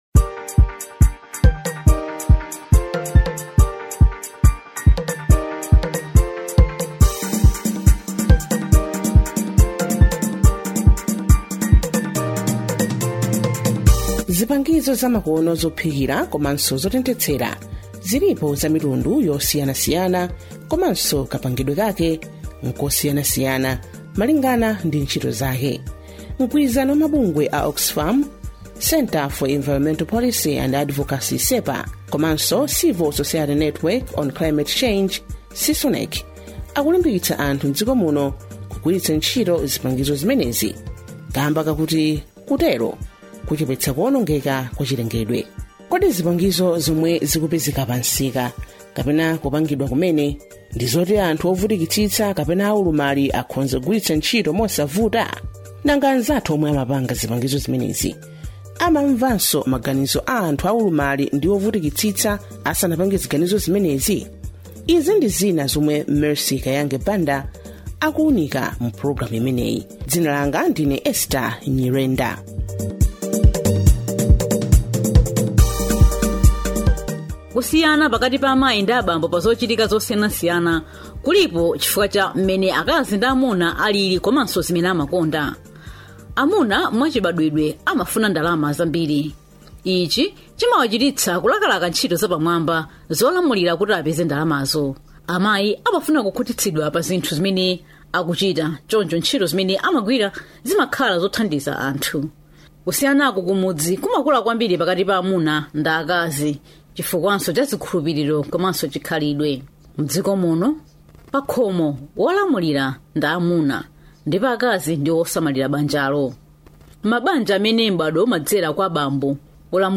DOCUMENTARY 5